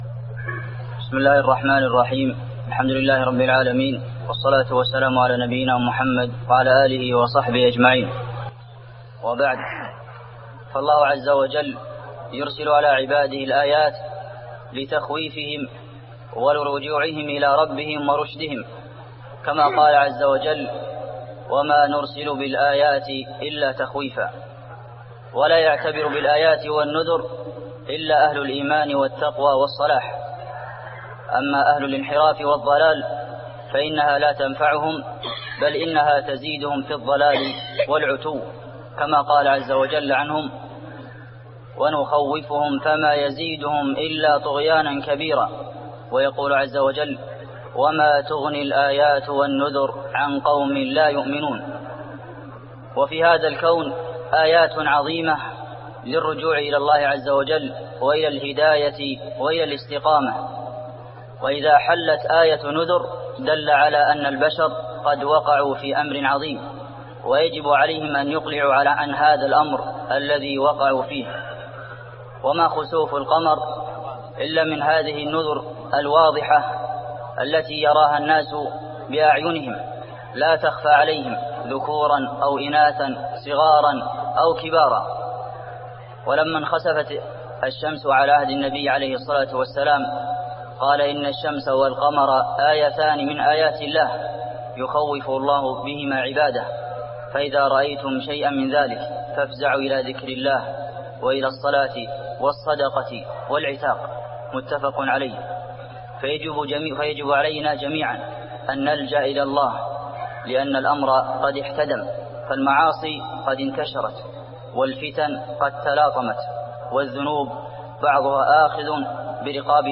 خطبة الخسوف المدينة - الشيخ عبدالمحسن القاسم
تاريخ النشر ١٤ شوال ١٤٢٠ هـ المكان: المسجد النبوي الشيخ: فضيلة الشيخ د. عبدالمحسن بن محمد القاسم فضيلة الشيخ د. عبدالمحسن بن محمد القاسم خطبة الخسوف المدينة - الشيخ عبدالمحسن القاسم